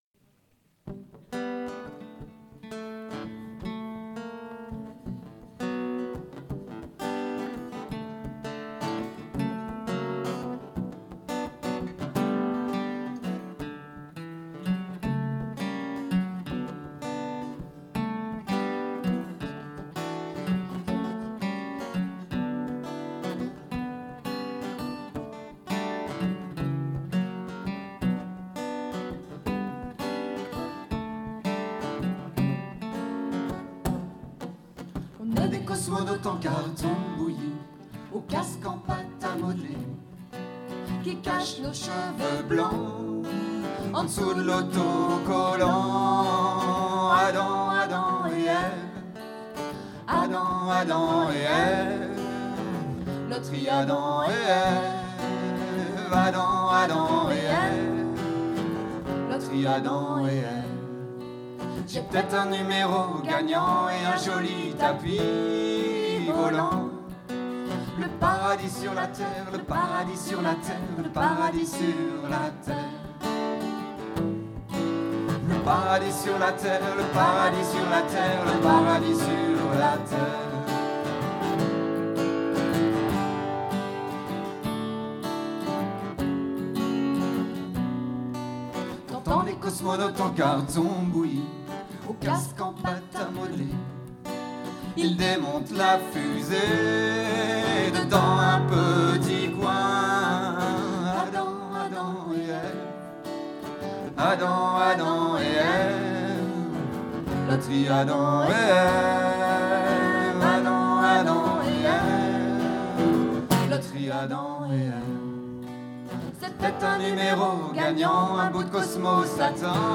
# extraits concert au pannonica, nantes, 22 avril 2006